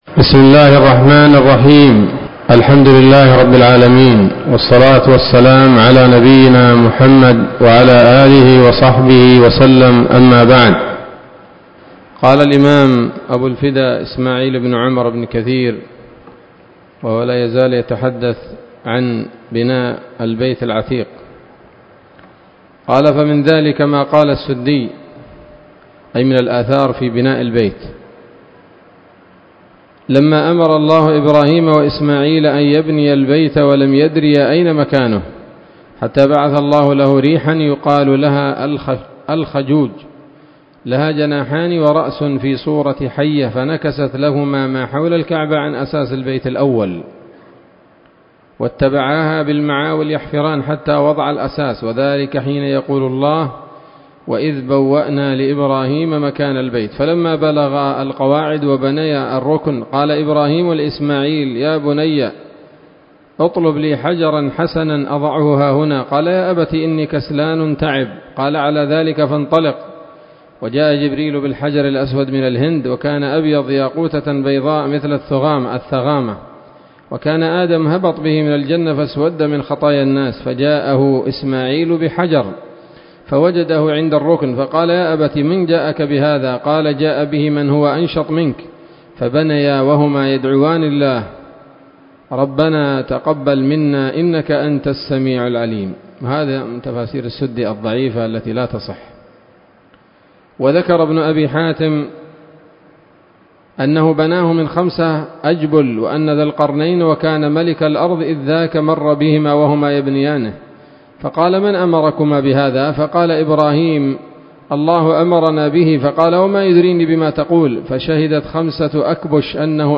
الدرس الثاني والخمسون من قصص الأنبياء لابن كثير رحمه الله تعالى